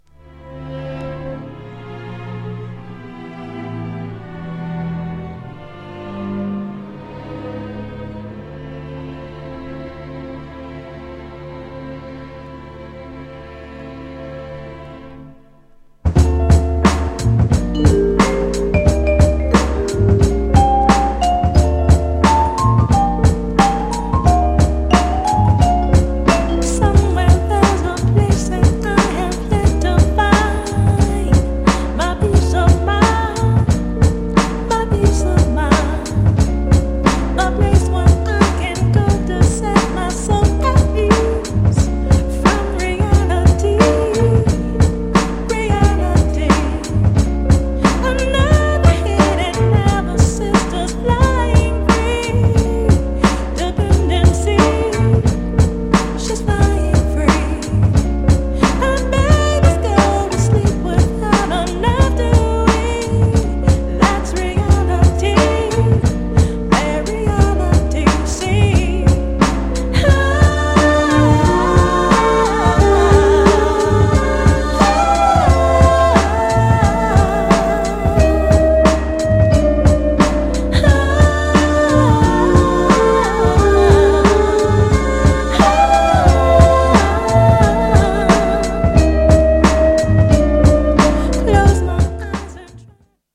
このグルーブ感はただ者じゃないッス!!
GENRE R&B
BPM 91〜95BPM